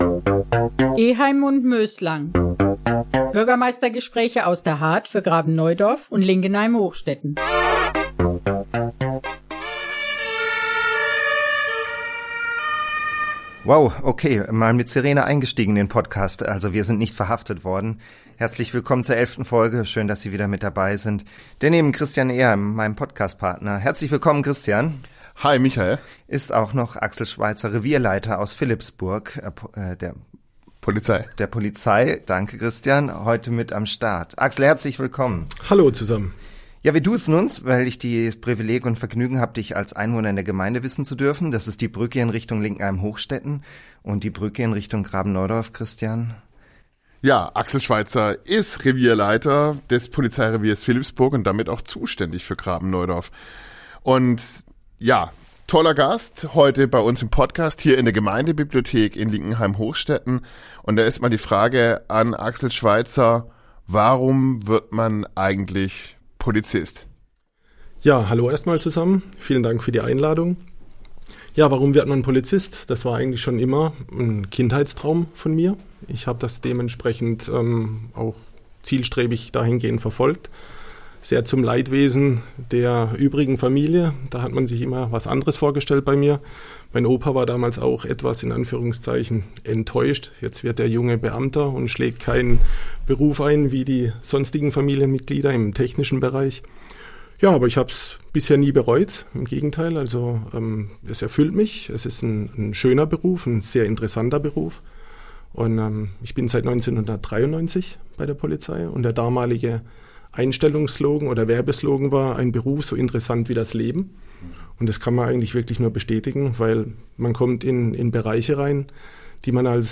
Eheim & Möslang – Der Bürgermeister-Podcast Folge 11 Heute mit Polizei ~ Eheim & Möslang - Bürgermeistergespräche aus der Hardt für Graben-Neudorf und Linkenheim-Hochstetten Podcast